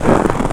STEPS Snow, Walk 07-dithered.wav